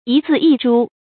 一字一珠 yī zì yī zhū
一字一珠发音
成语注音 ㄧ ㄗㄧˋ ㄧ ㄓㄨ